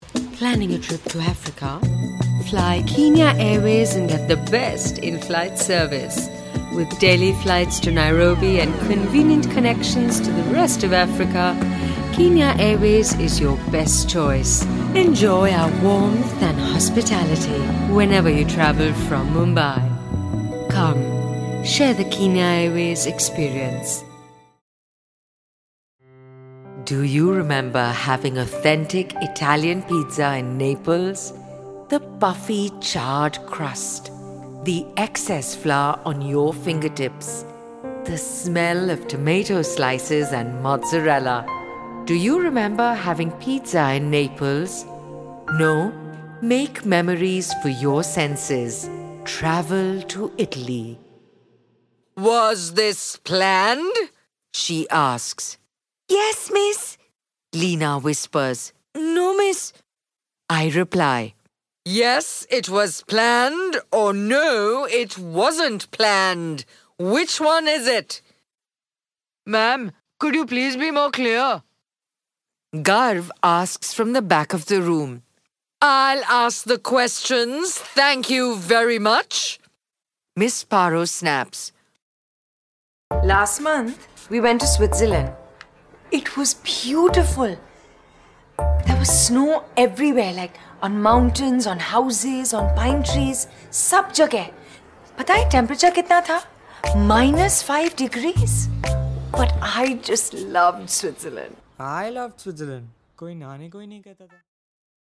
VOICE REELS
english-acting-vo-reel-copy-1.mp3